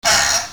nail3.mp3